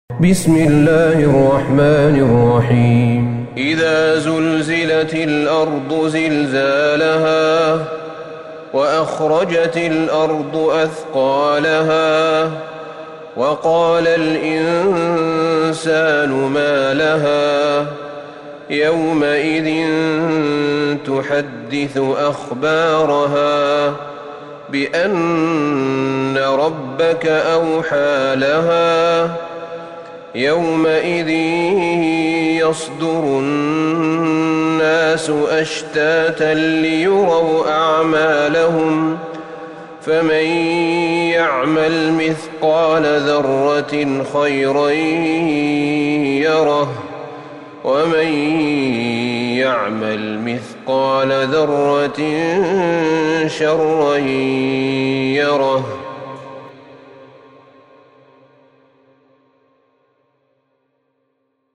سورة الزلزلة Surat Az-Zalzalah > مصحف الشيخ أحمد بن طالب بن حميد من الحرم النبوي > المصحف - تلاوات الحرمين